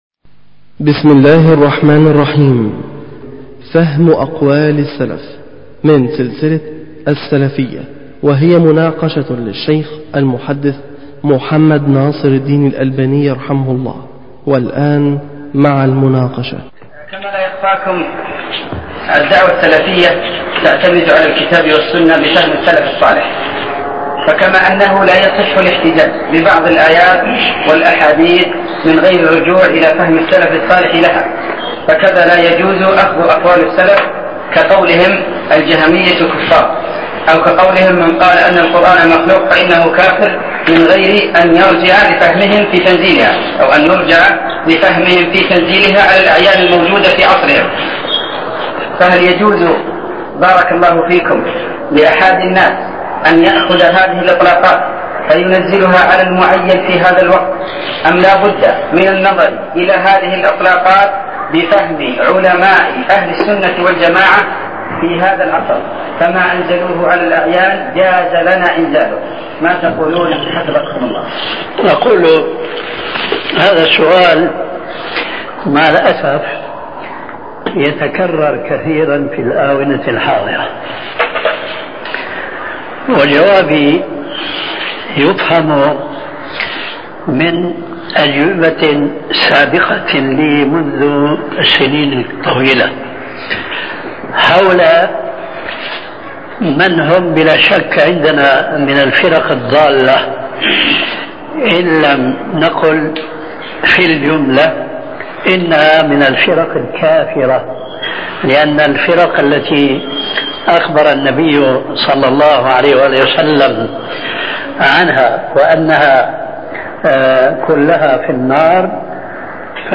شبكة المعرفة الإسلامية | الدروس | فهم أقوال السلف |محمد ناصر الدين الالباني